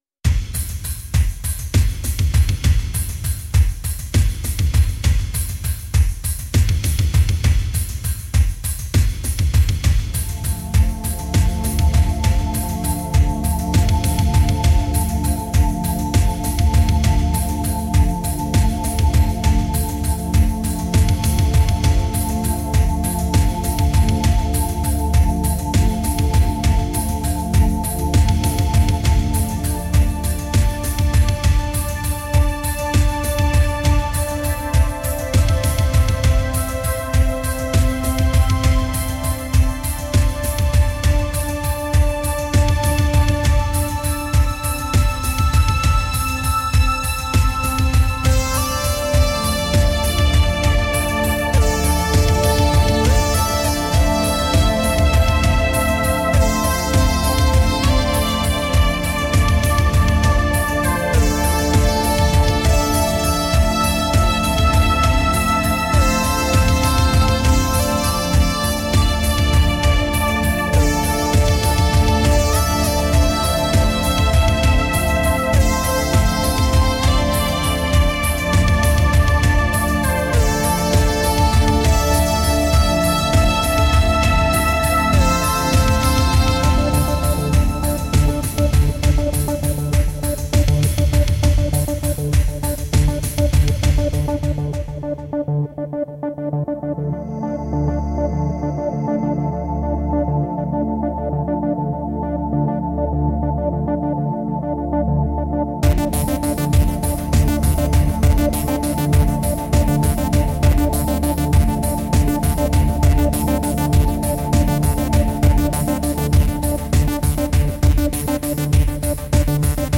Sublime and ethereal electronic music.
Tagged as: Electronica, Techno